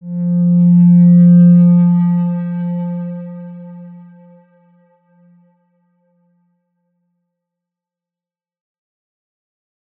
X_Windwistle-F2-mf.wav